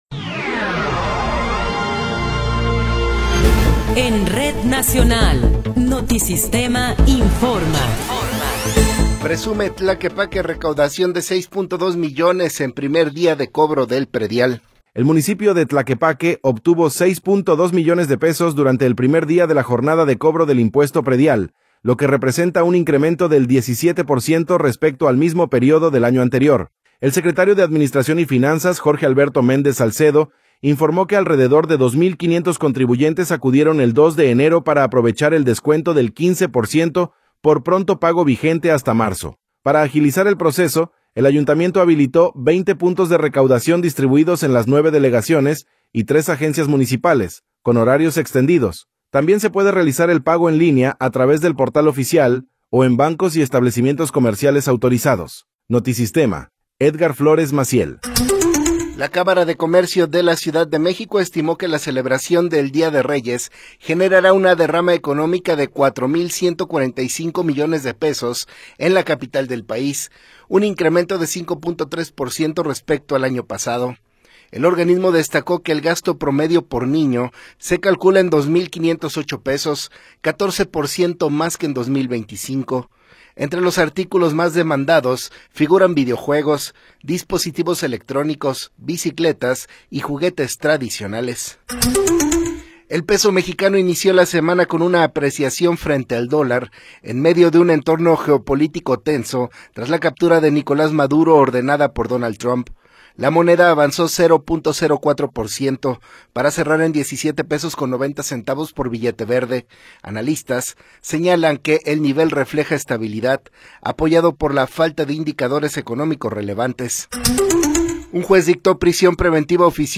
Noticiero 17 hrs. – 5 de Enero de 2026
Resumen informativo Notisistema, la mejor y más completa información cada hora en la hora.